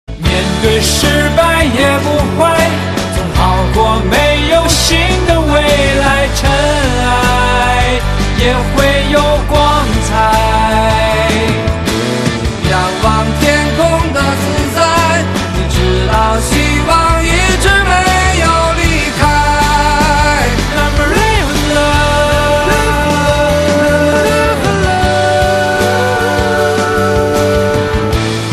华语歌曲
国语